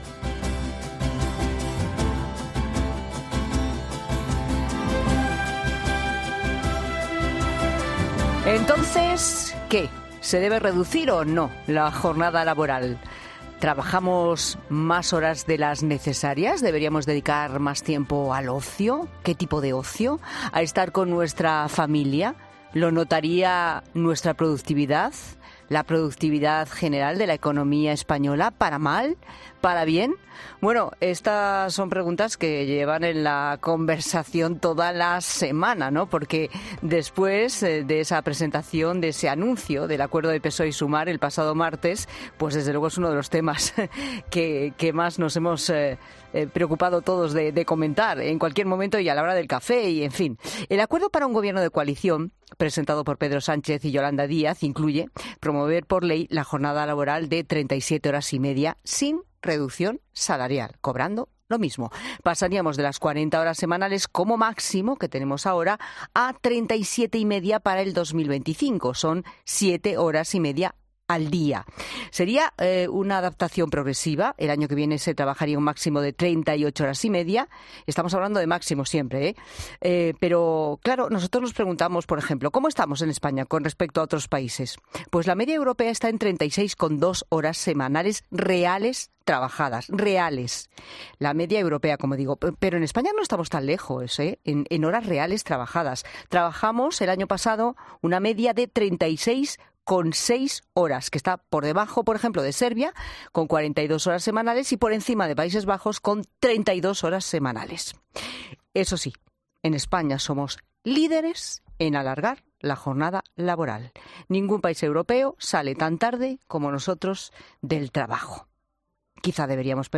Escucha la entrevista completa con las expertas en gestión del tiempo